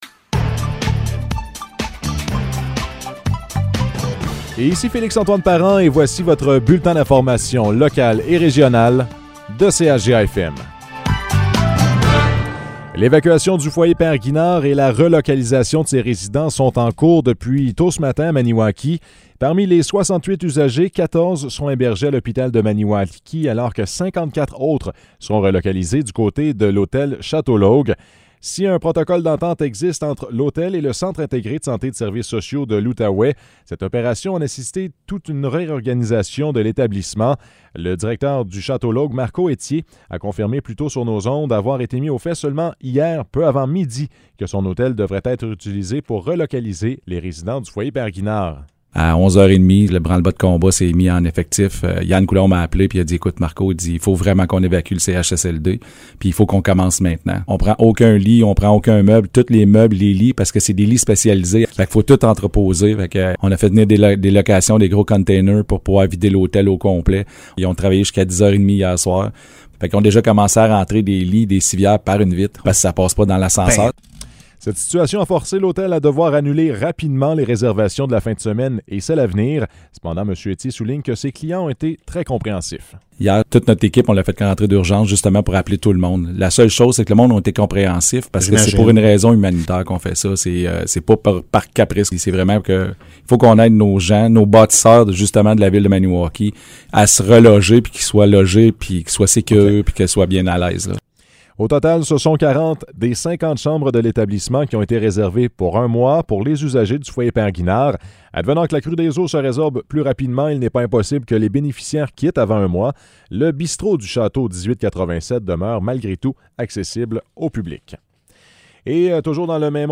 Nouvelles locales - 20 mai 2022 - 12 h